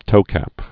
(tōkăp)